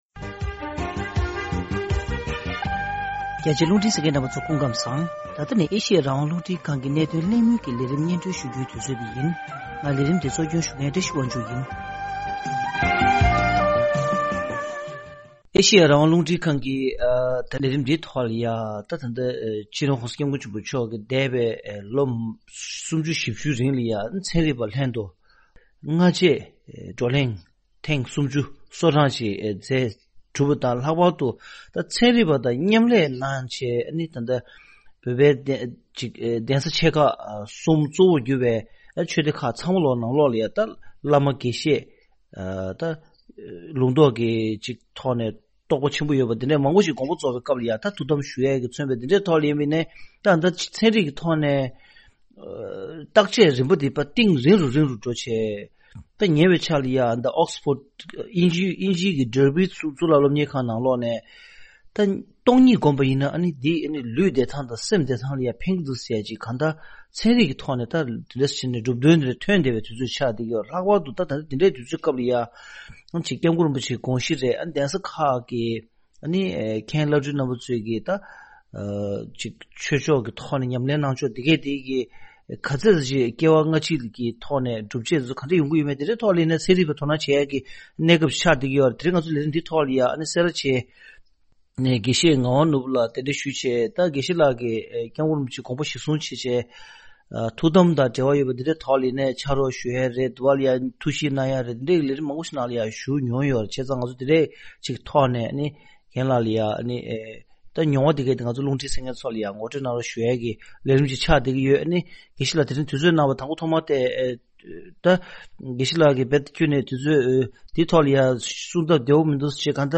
གནད་དོན་གླེང་མོལ་གྱི་ལས་རིམ་འདིའི་ནང་།